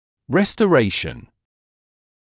[ˌrestə’reɪʃn][ˌрэстэ’рэйшн]реставрация, восстановление